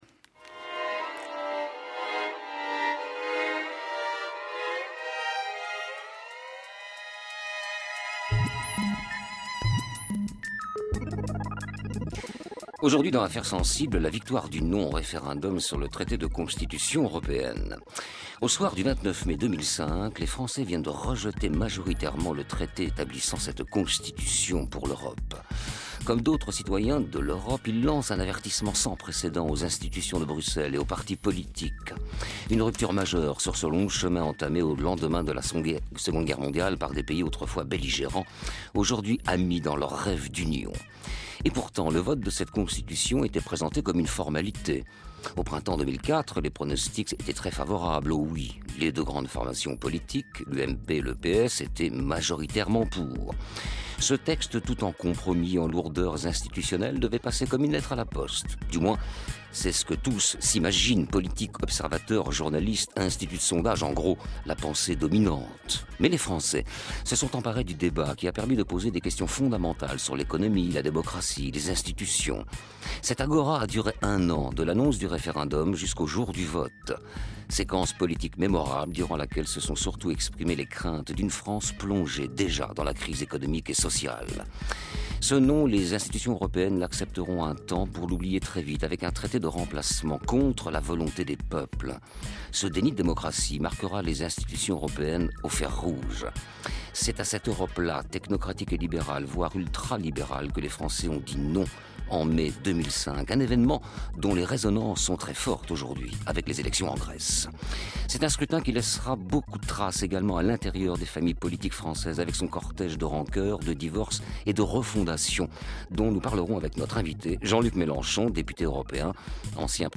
Le 26 janvier 2015, Jean-Luc Mélenchon était l’invité de France Inter dans l’émission « Affaires sensibles » pour parler du « non » au référendum de 2005 (JLM à partir de 31:12) :